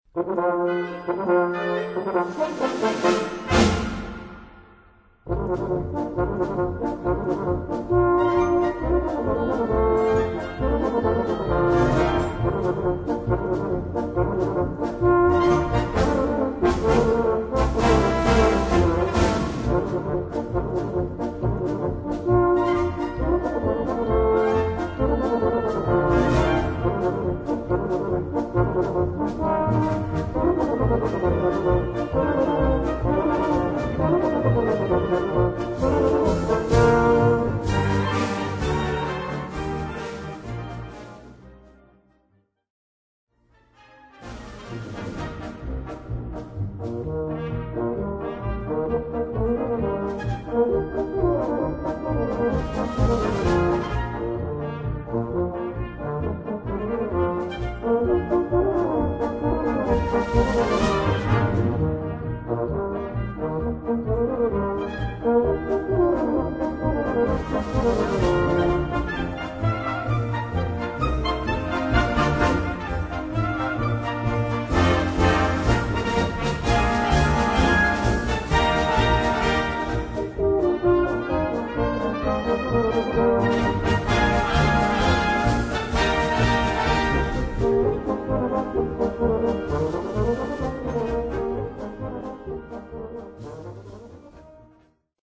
Gattung: Solo Tenorgruppe
Besetzung: Blasorchester